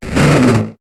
Cri de Malosse dans Pokémon HOME.